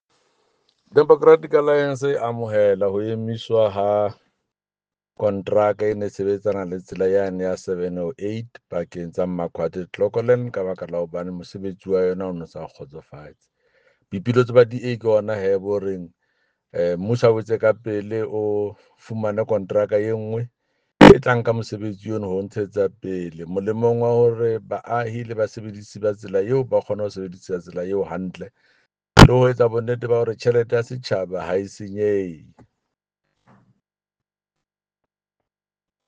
Sesotho soundbites by Jafta Mokoena MPL
Jafta-Ses-1.mp3